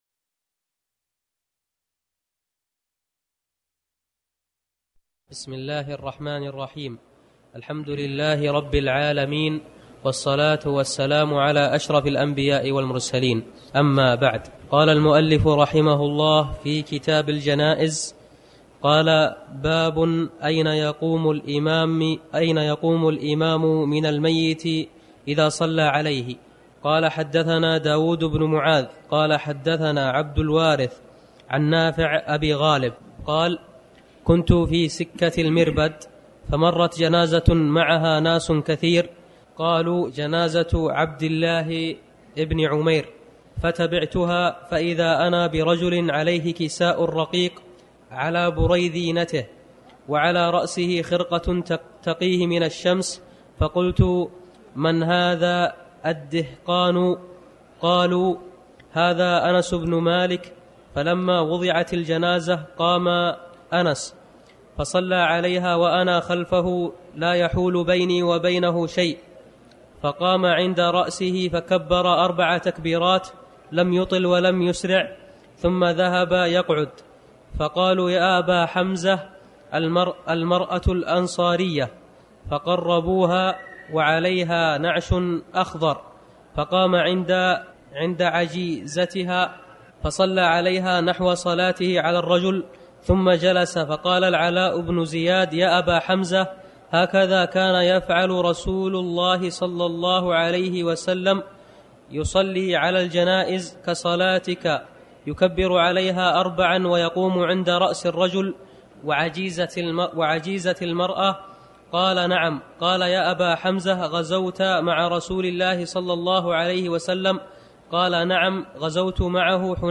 تاريخ النشر ٢ ربيع الأول ١٤٤٠ هـ المكان: المسجد الحرام الشيخ